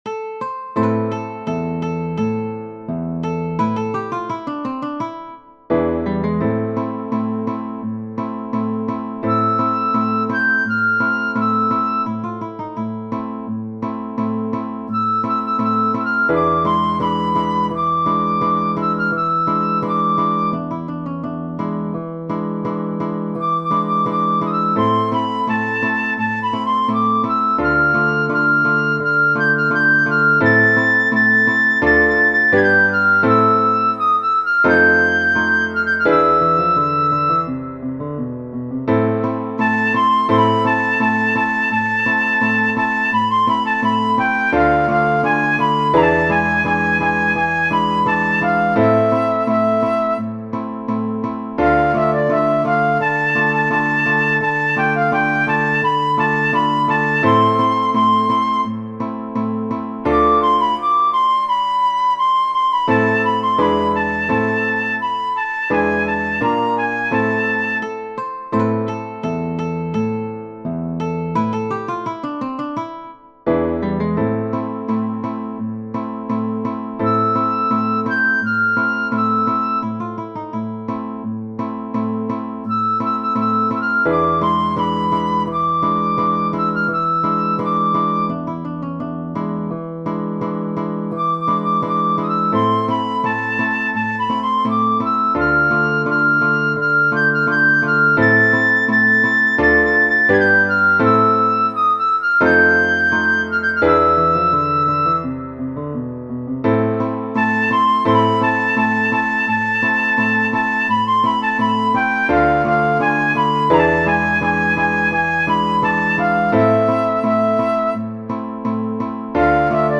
Genere: Napoletane
basato su un suadente ritmo di beguine